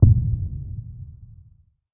描述：我为游戏《无敌》创作的手榴弹声音。从2个低音鼓的样本开始，把它们的音调调低，加上超速失真，根据口味进行EQ。
Tag: 热潮 手榴弹 爆炸